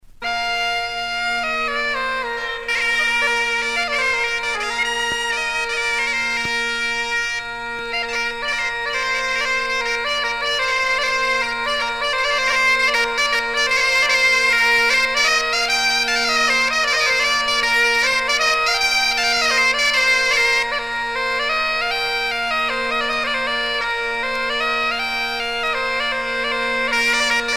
Usage d'après l'analyste gestuel : danse